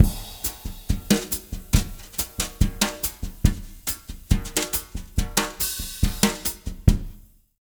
140BOSSA05-R.wav